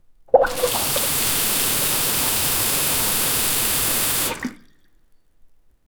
wash-my-head-with-water-x3m6plb5.wav